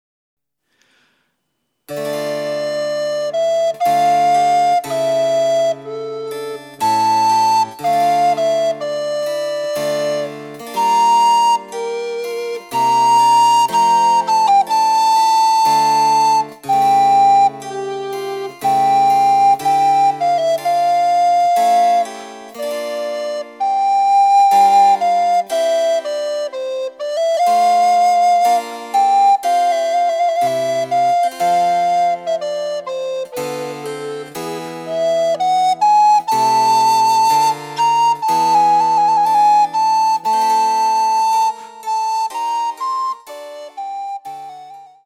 ■リコーダーによる演奏
リコーダー演奏
チェンバロ（電子楽器）演奏